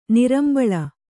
♪ nirambaḷa